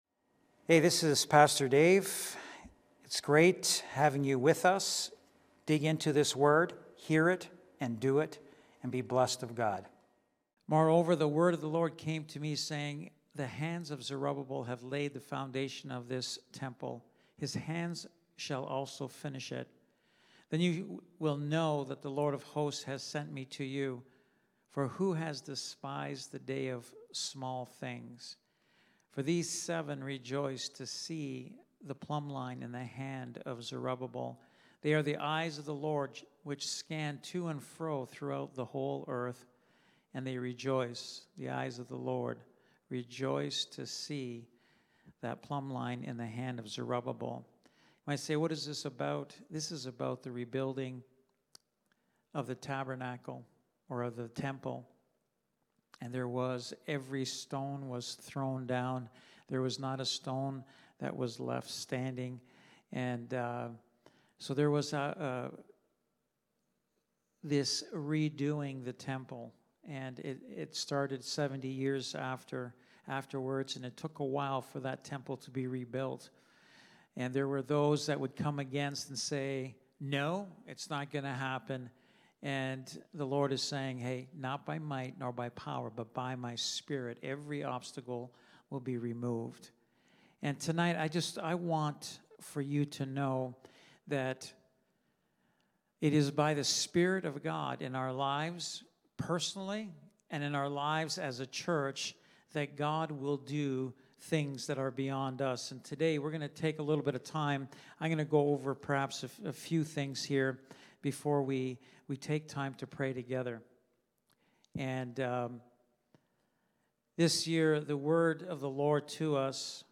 Wednesday Night Bible Study